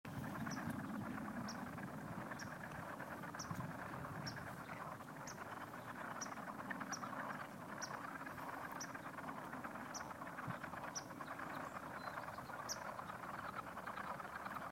Southern Leopard Frogs (mating calls)
Audio clip 1 of 2, recorded on 06 March 2012 at Huntley Meadows Park, Fairfax County, Virginia USA.